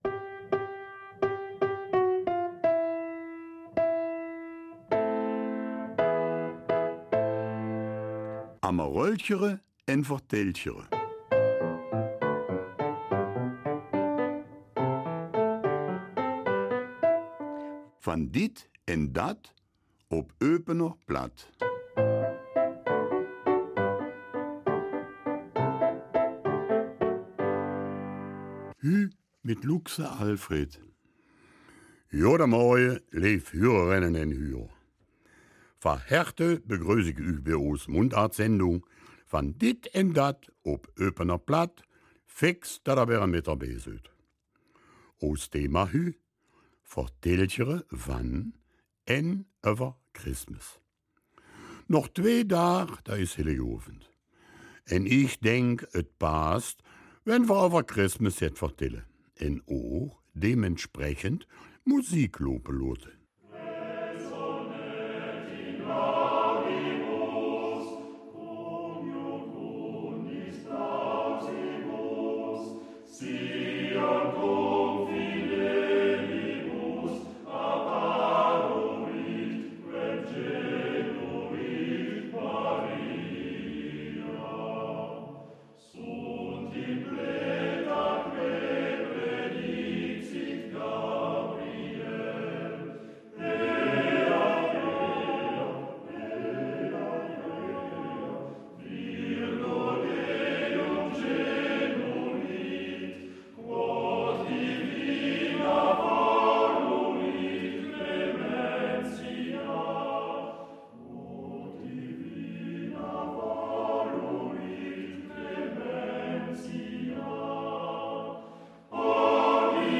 Eupener Mundart - 22. Dezember